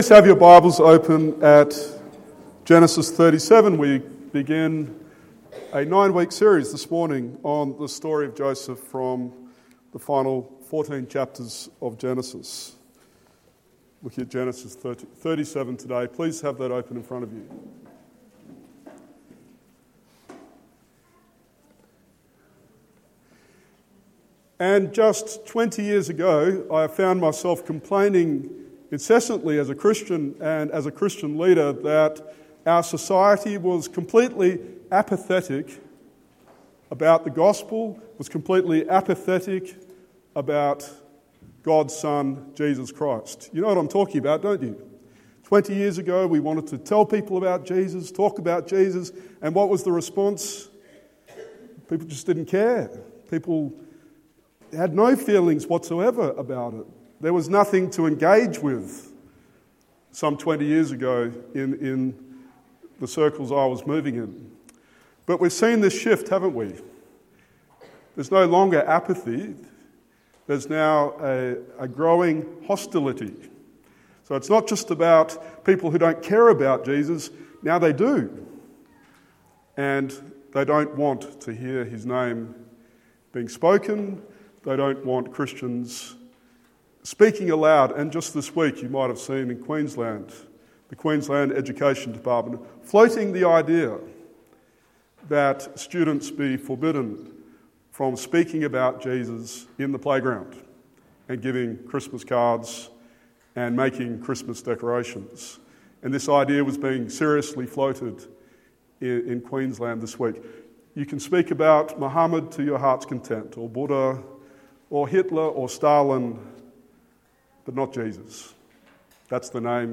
Genesis 37:1-11 Sermon